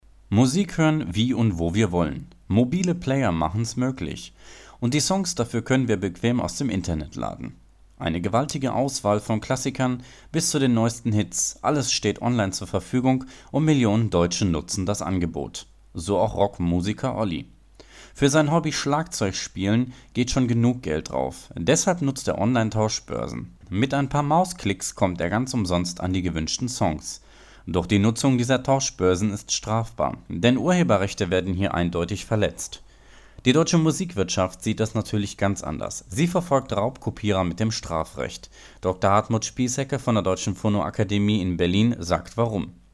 deutscher Sprecher
Sprechprobe: eLearning (Muttersprache):
german voice over artist